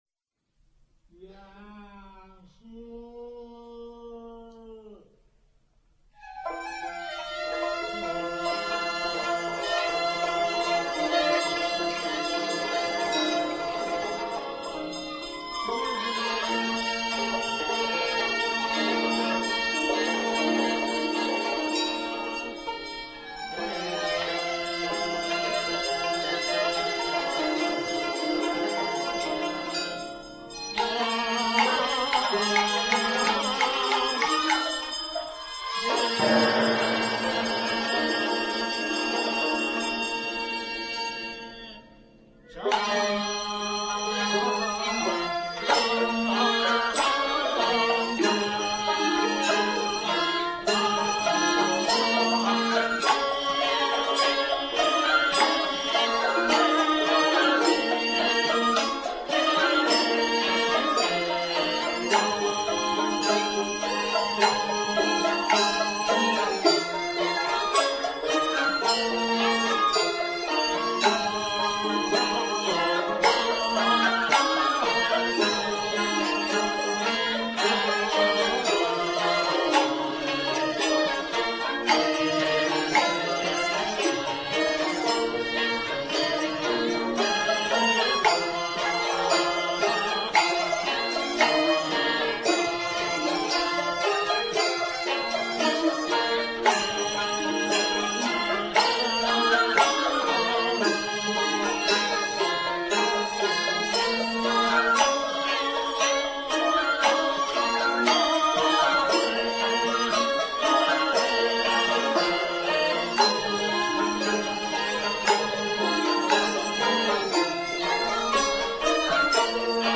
纳西古乐
很古朴的音乐